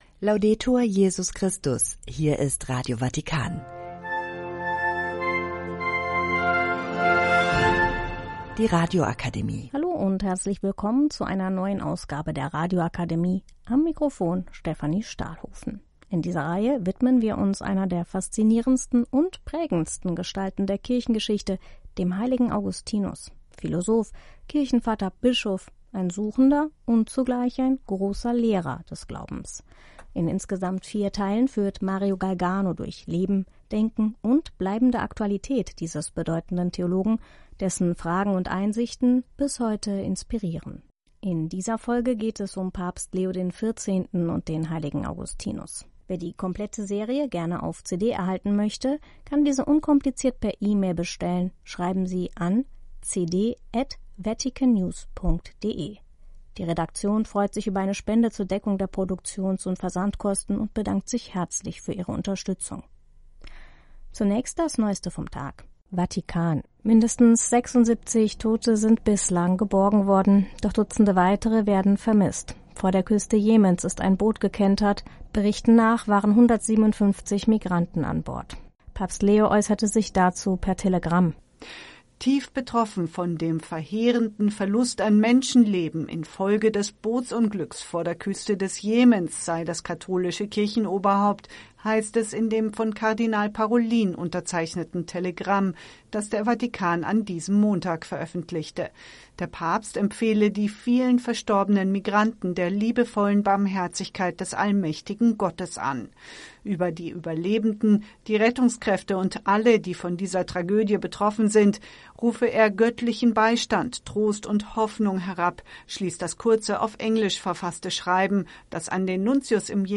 Treffpunkt Weltkirche - Nachrichtenmagazin (18 Uhr).